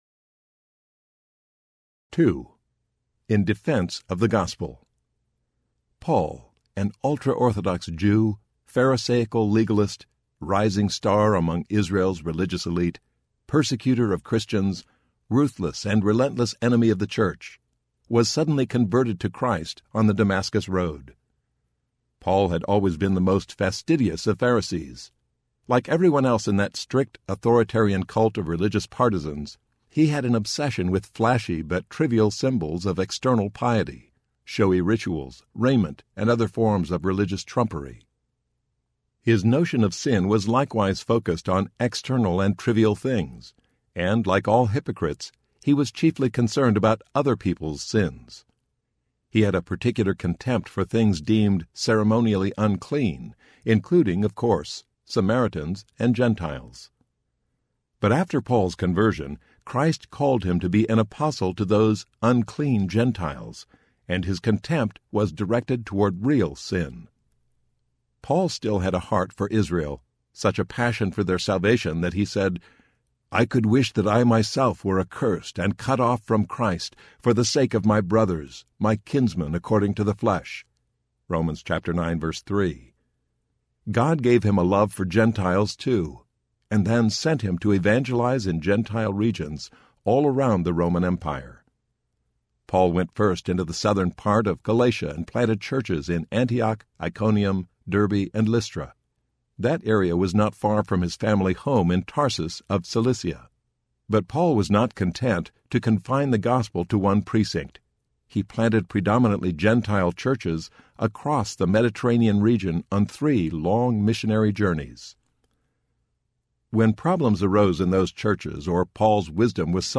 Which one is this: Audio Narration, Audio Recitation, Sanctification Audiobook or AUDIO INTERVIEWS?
Sanctification Audiobook